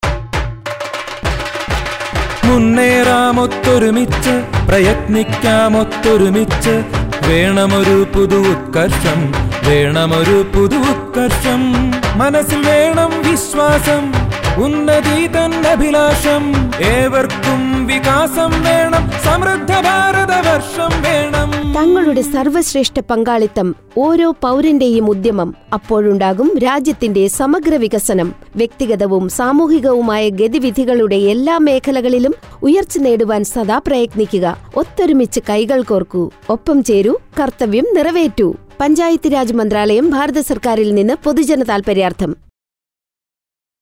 133 Fundamental Duty 10th Fundamental Duty Strive for excellence Radio Jingle Malayamlam